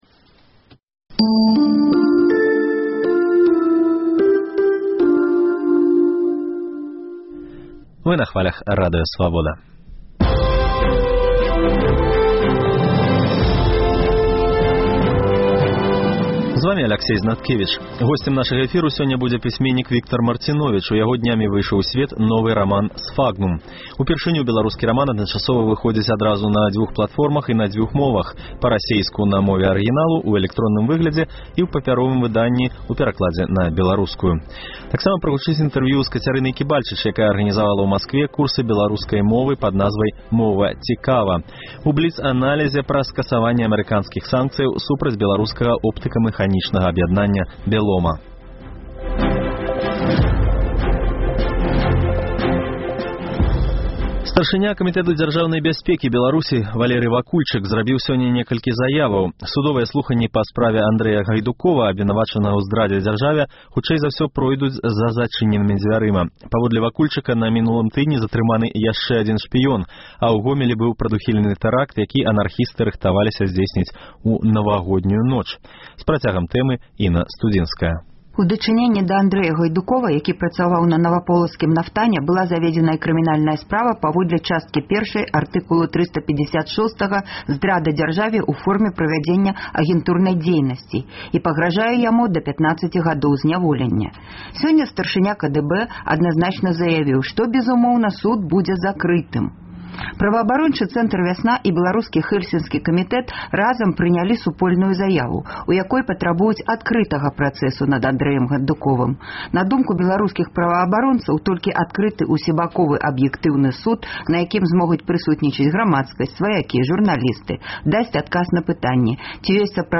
Таксама прагучыць інтэрвію